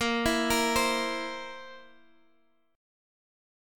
A#sus2b5 Chord